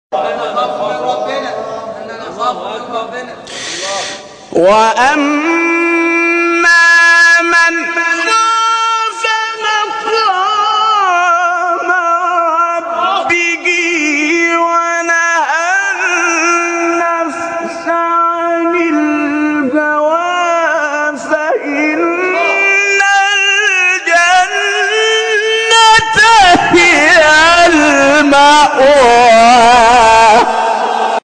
شبکه اجتماعی: مقاطعی صوتی از تلاوت قاریان ممتاز کشور را می‌شنوید.